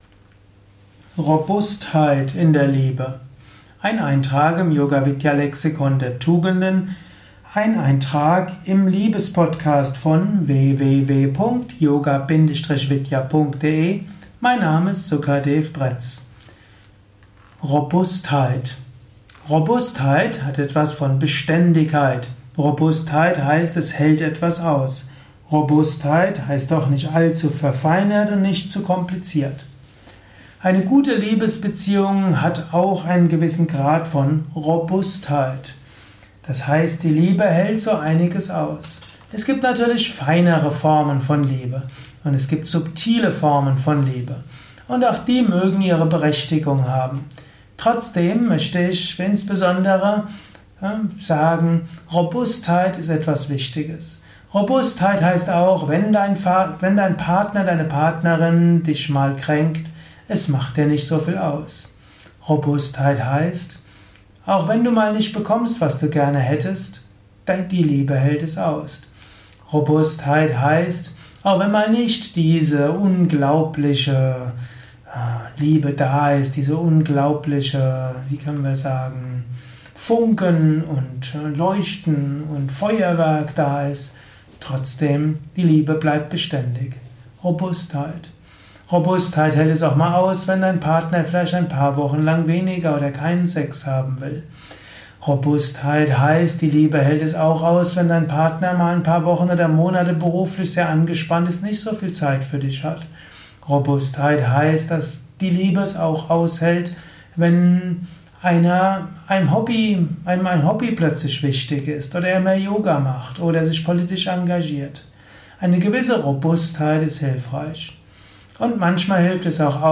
Dieser Kurzvortrag